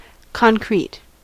Ääntäminen
IPA: /bə.'ʃtɪmt/